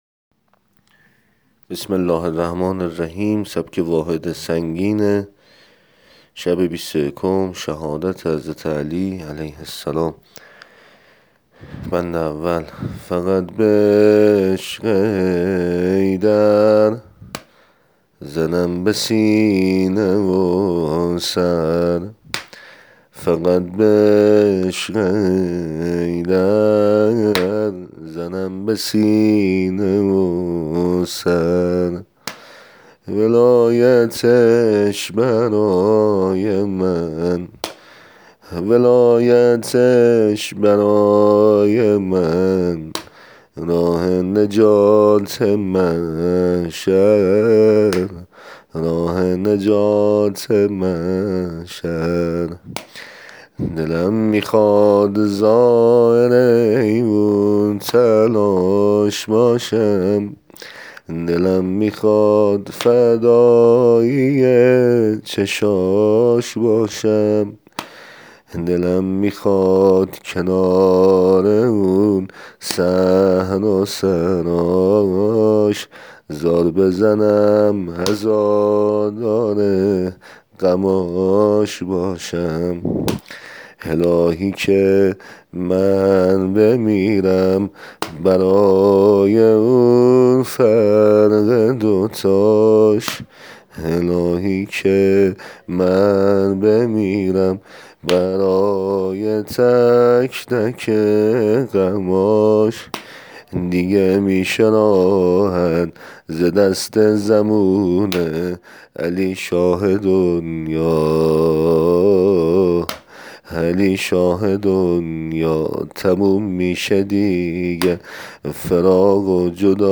عنوان : سبک واحد سنگین حضرت علی (ع)